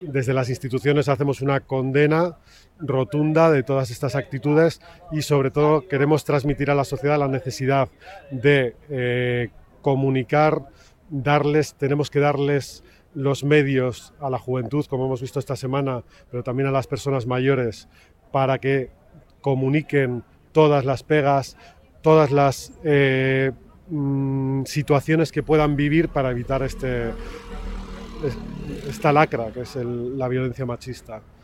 El Subdelegado del Gobierno en Bizkaia destaca la necesidad de dotar de medios a los jóvenes